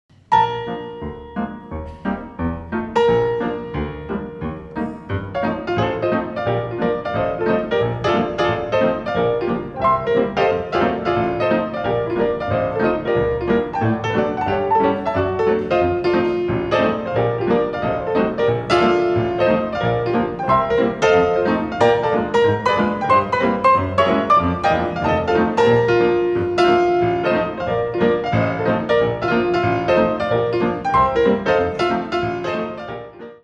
voc)in different solo, duo and trio combinations